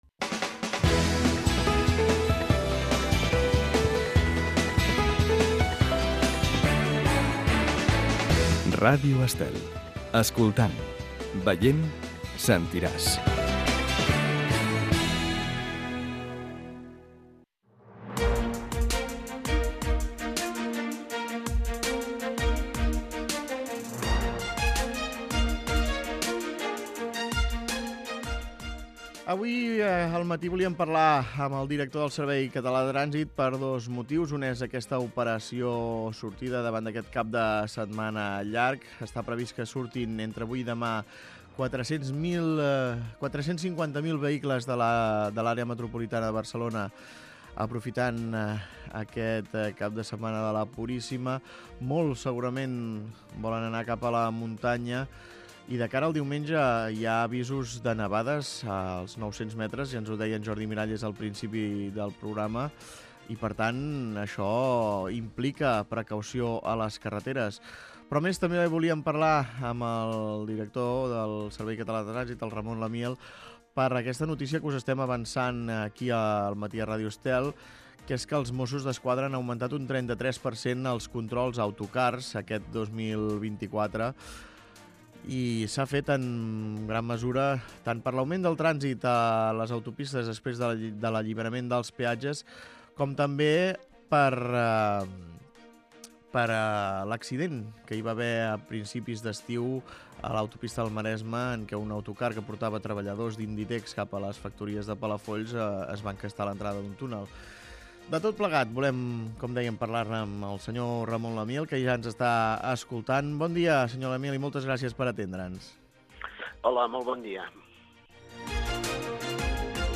Escolta l'entrevista a Ramon Lamiel, director del Servei Català de Trànsit